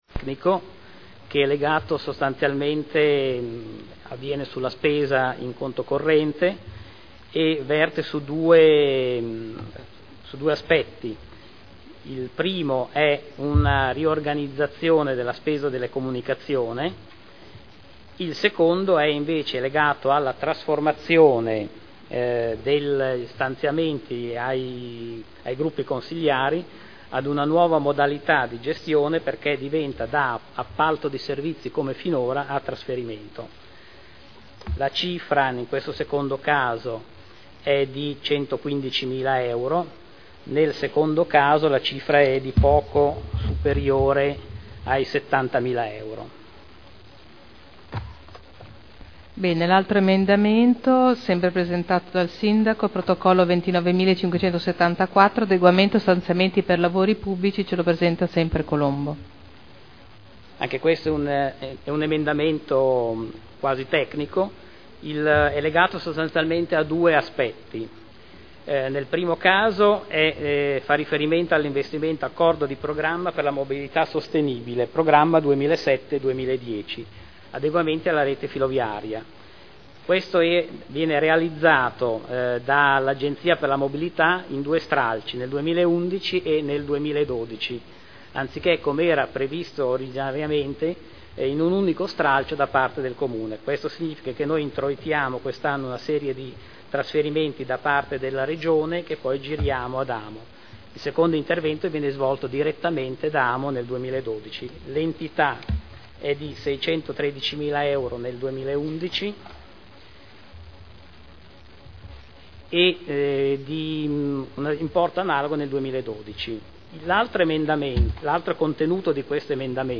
Alvaro Colombo — Sito Audio Consiglio Comunale